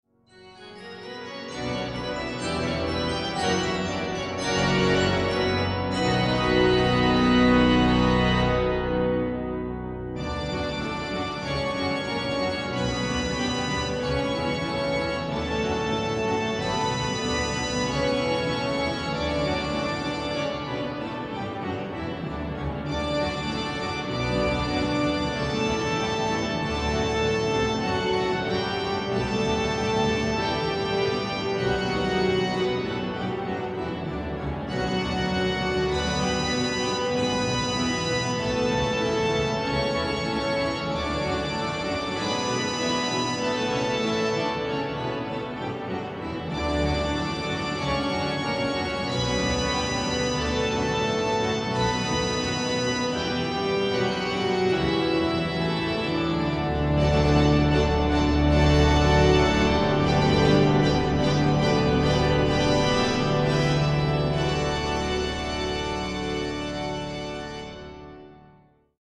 Die große Domorgel von Valladolid ist eine digitale Allen Orgel mit 19.000 Pfeifen.
wie Cembalo, Klavier, Harfe oder Panflöte,
immer begleitet von den Orgelpfeifen.
Einmalige Musik in der herrlichen Akustik des Doms zu Valladolid
mit einem Nachhall von 8 Sekunden !